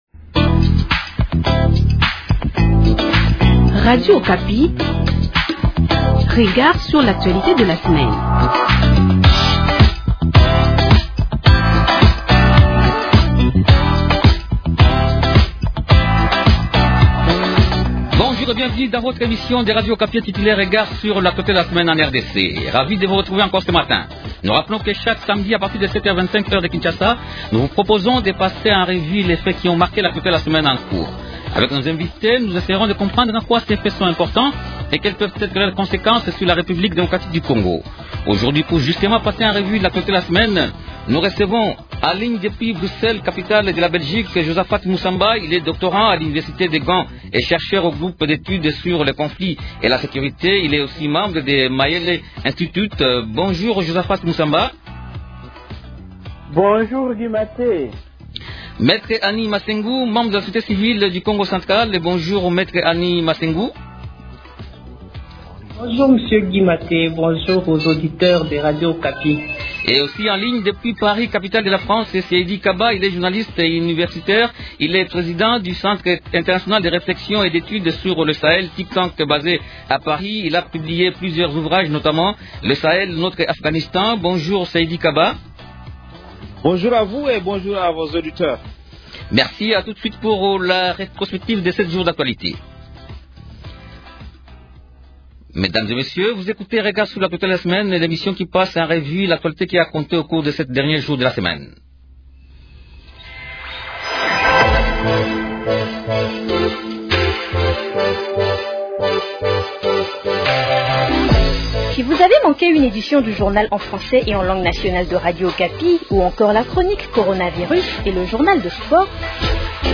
Invités : -En ligne depuis Bruxelles